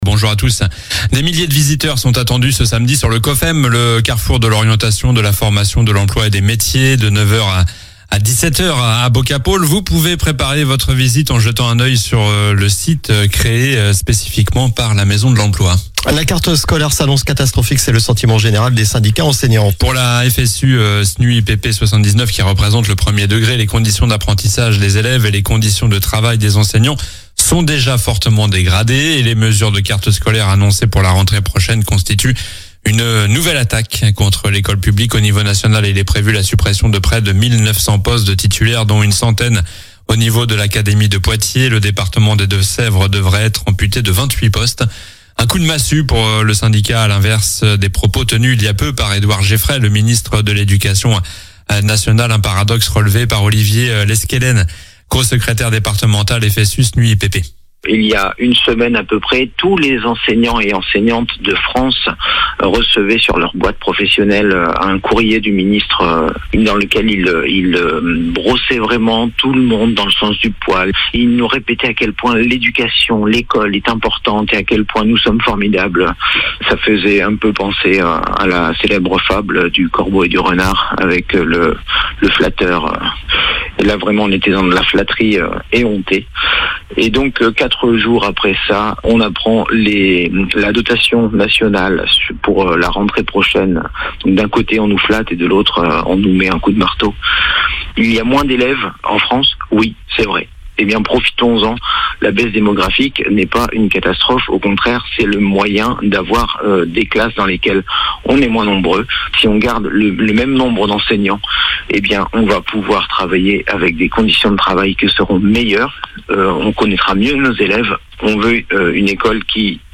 Journal du samedi 31 janvier